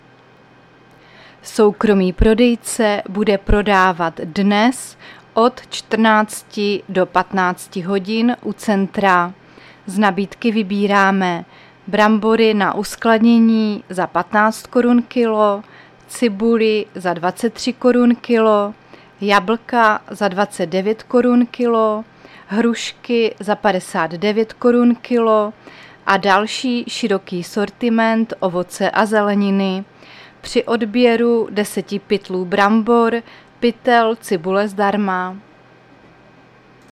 Záznam hlášení místního rozhlasu 22.9.2023